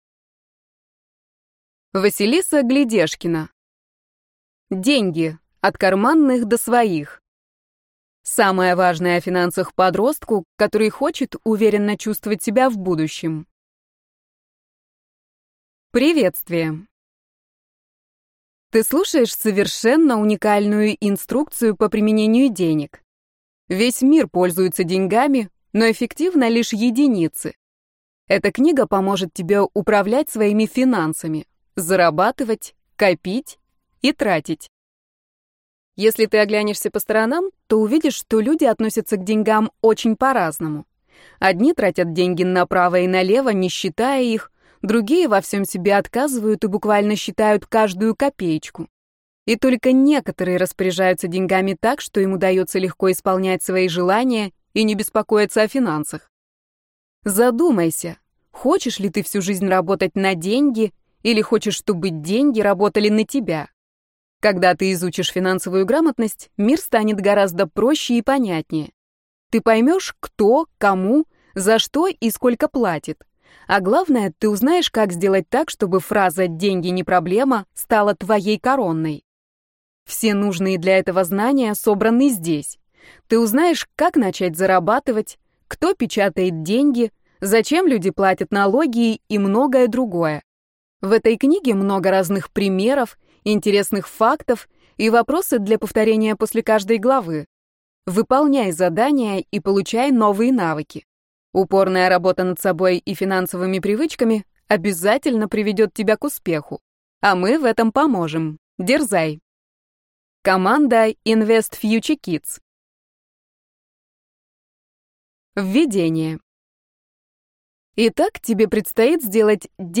Аудиокнига Деньги: от карманных до своих. Самое важное о финансах подростку, который хочет уверенно чувствовать себя в будущем | Библиотека аудиокниг